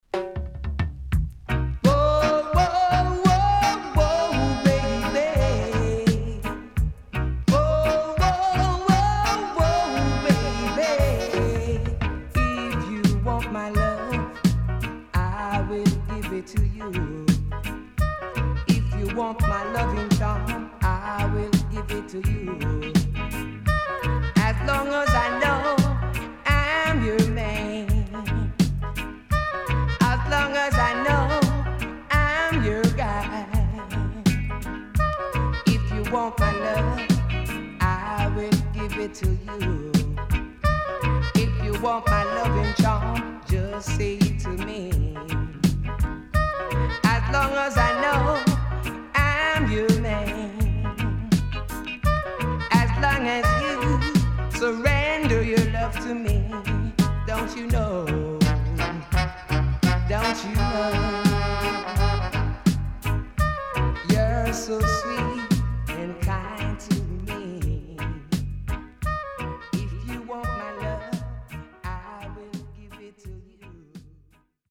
HOME > LP [DANCEHALL]  >  EARLY 80’s
SIDE A:うすいこまかい傷ありますがノイズあまり目立ちません。